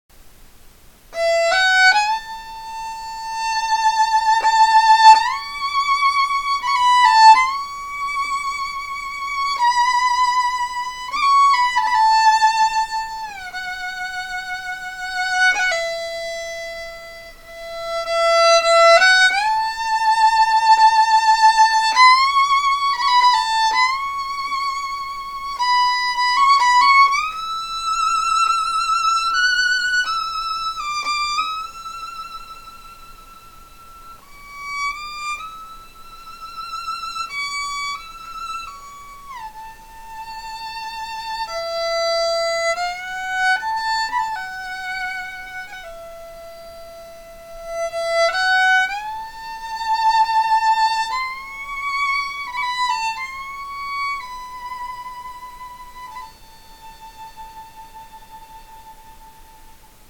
ON THE VIOLIN!